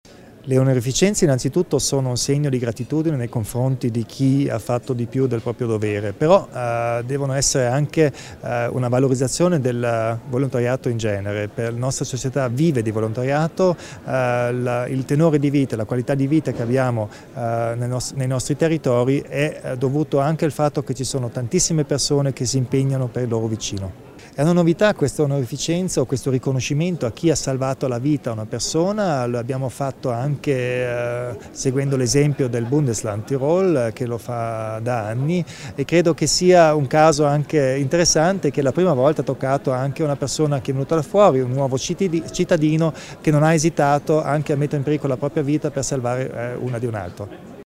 Con queste parole, il presidente della Provincia di Bolzano, Arno Kompatscher ha introdotto quest’oggi (18 settembre) a Castel Tirolo la cerimonia di consegna delle Croci al merito del Land Tirolo.